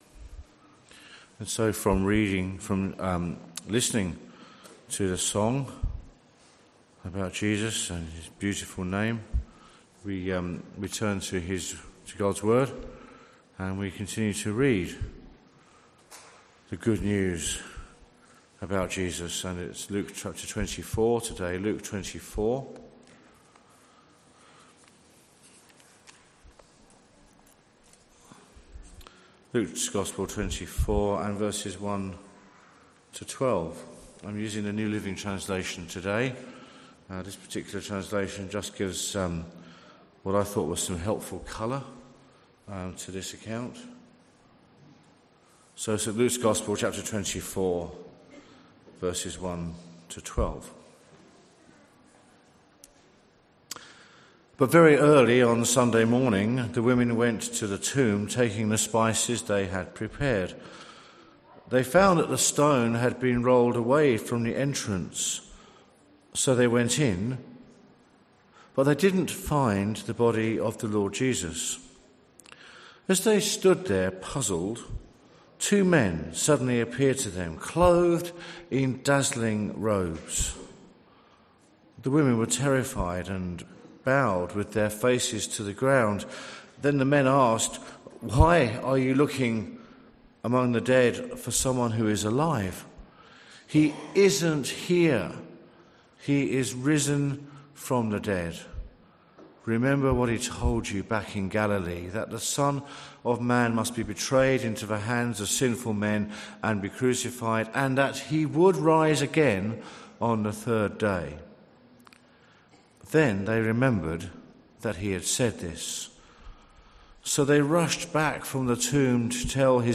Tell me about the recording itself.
Bible reading and sermon from the 10AM meeting at Newcastle Worship & Community Centre of The Salvation Army. The Bible reading was taken from Luke 24:1-12.